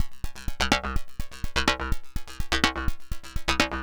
tx_synth_125_phaseflt_C3.wav